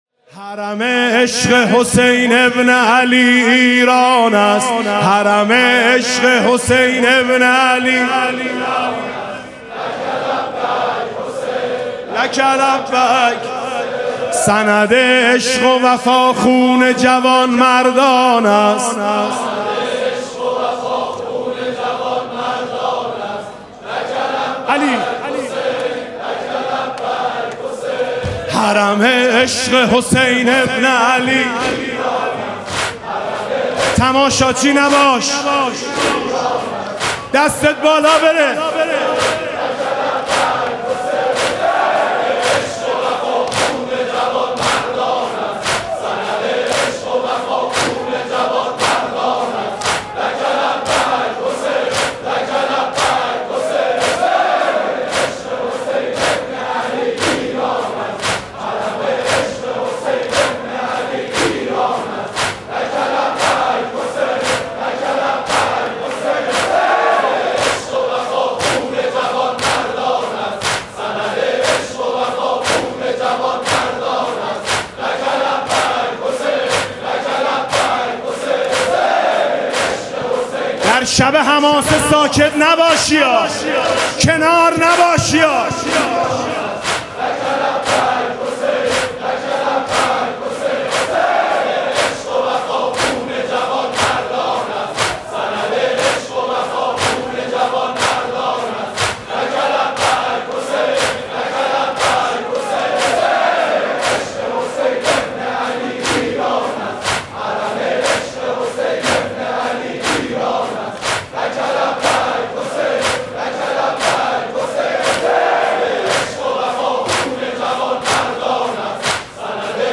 (دودمه)